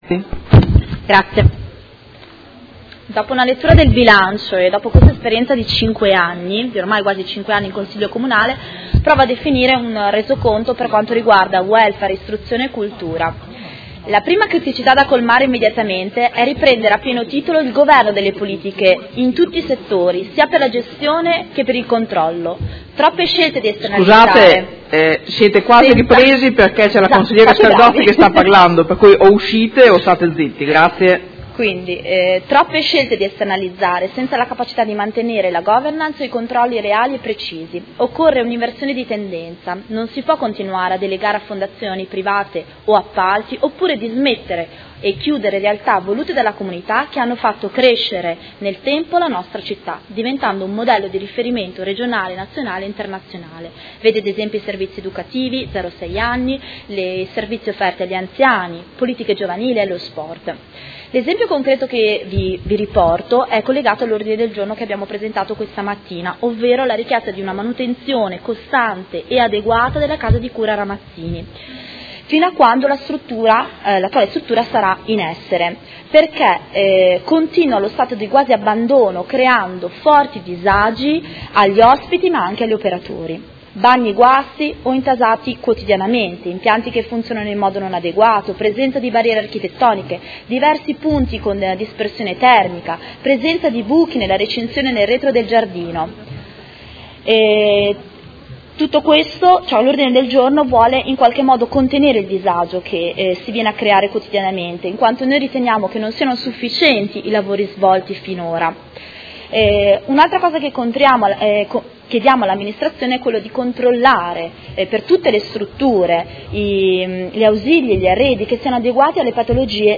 Seduta del 20/12/2018. Dibattito su delibera di bilancio, Ordini del Giorno, Mozioni ed emendamenti
Audio Consiglio Comunale